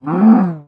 1.21.5 / assets / minecraft / sounds / mob / cow / hurt2.ogg
hurt2.ogg